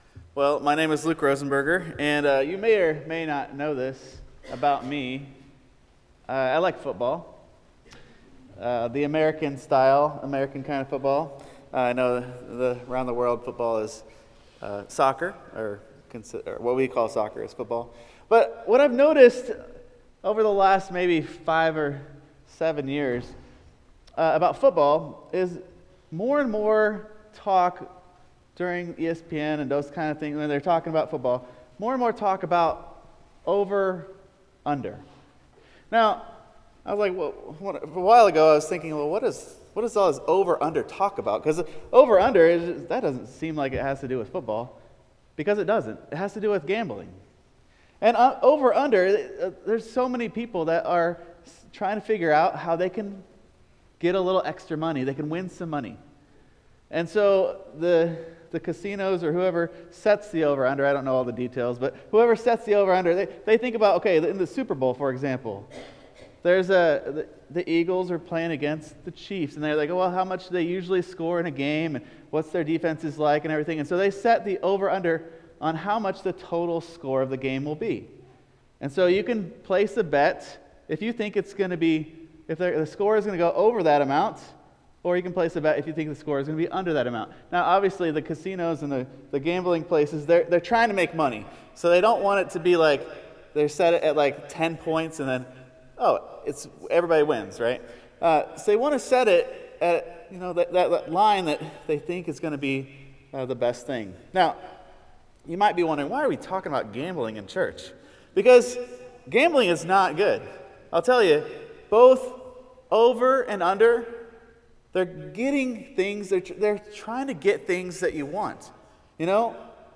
1 Iran, Israel and US power: MEMO in Conversation with Trita Parsi 30:26 Play Pause 2h ago 30:26 Play Pause Toista myöhemmin Toista myöhemmin Listat Tykkää Tykätty 30:26 Has Iran's direct retaliatory strike on Israel been a major blow to Israel's deterrence capacity or a blow to Tehran on a world stage? MEMO speaks to leading experts on US-Iran relations Dr Trita Parsi.